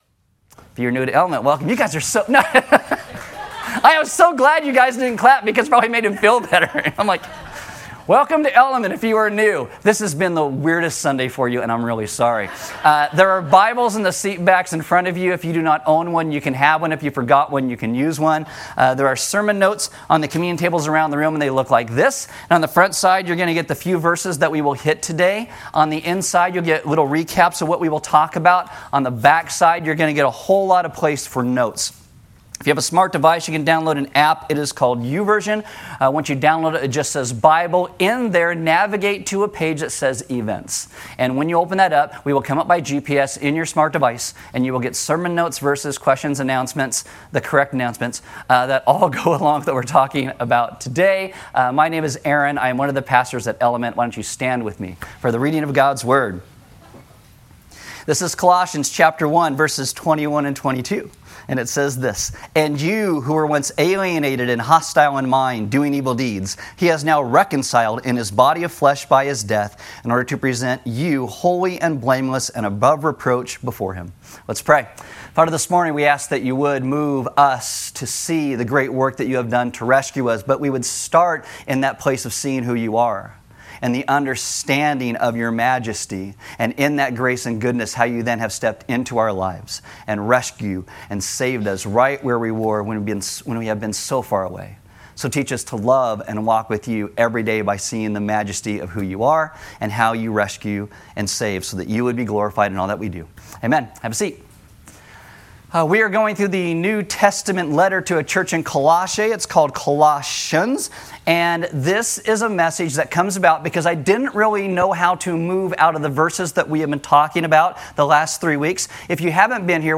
A message from the series "Colossians."